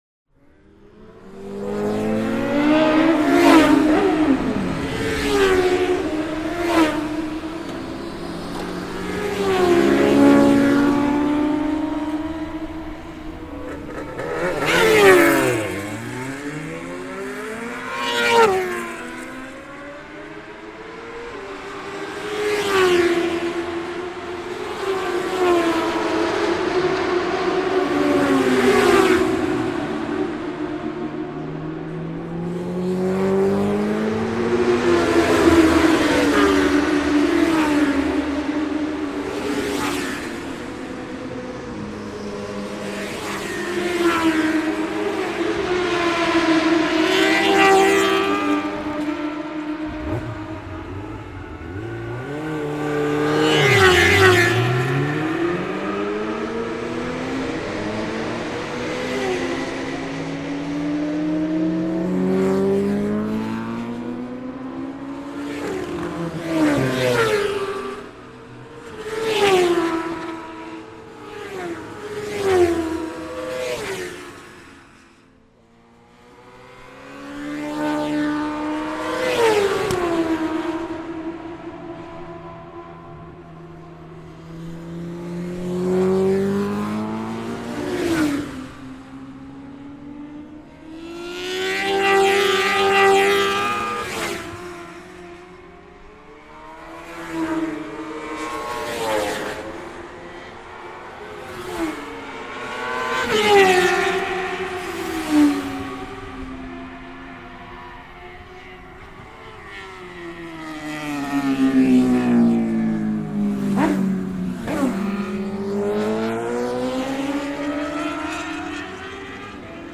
oreilles avec de délicieux chants de moteurs et d'échappements qui nous font si souvent
LE GSX-R DANS TOUTE SA SPLENDEUR :
The gsx-r.mp3